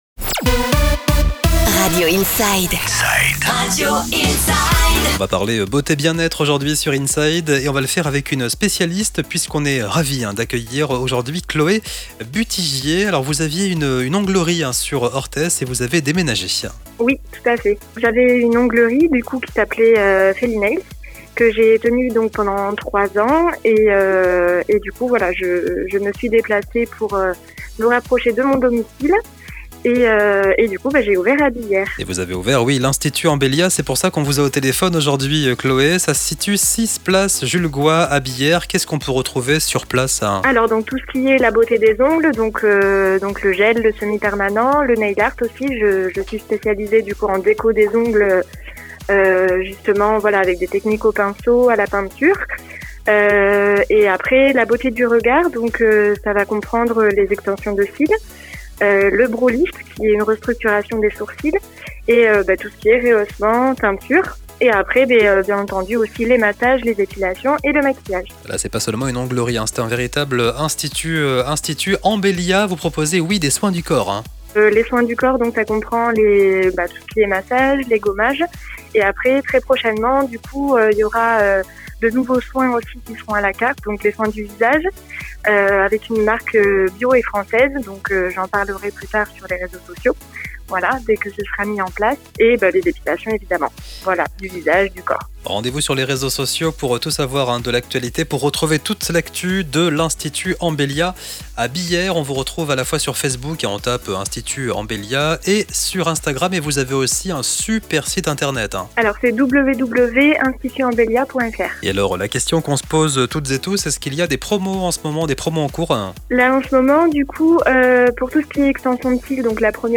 INTERVIEW Institut Ambellia, sur Radio Inside - INSIDE